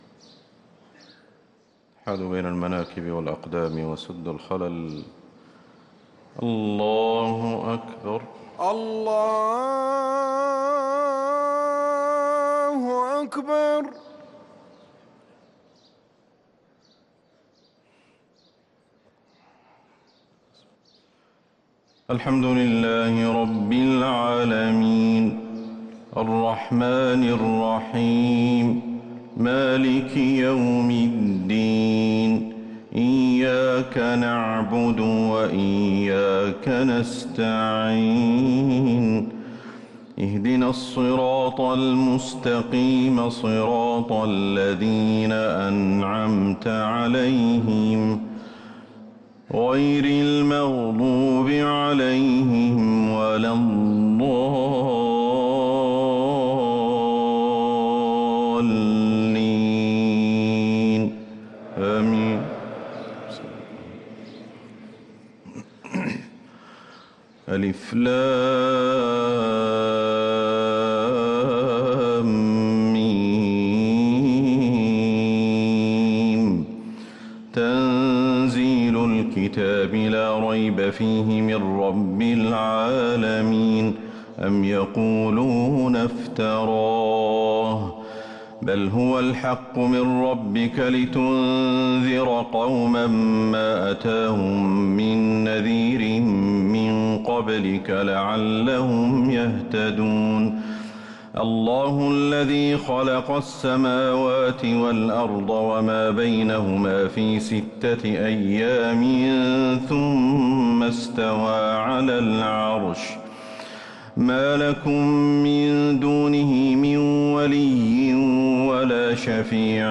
صلاة الفجر للقارئ أحمد الحذيفي 18 شعبان 1444 هـ